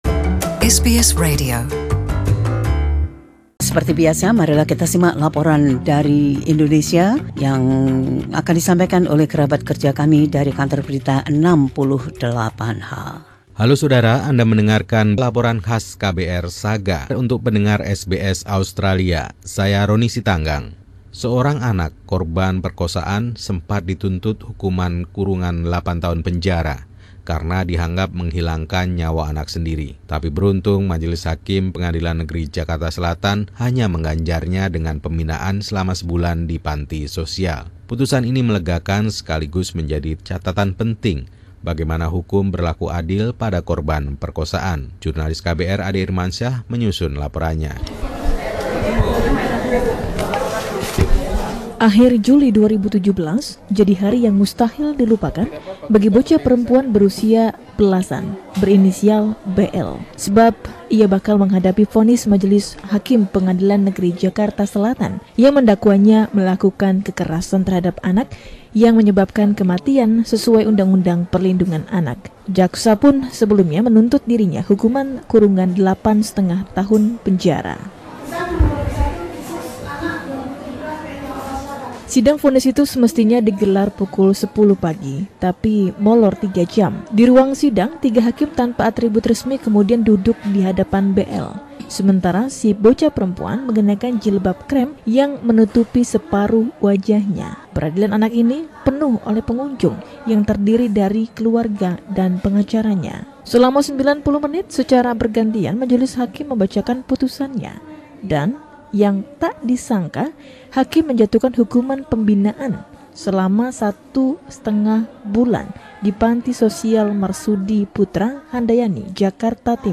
Laporan khusus tim KBR 68H ini menceritakan bagaimana seorang korban pemerkosaan mendapatkan keadilan di pengadilan.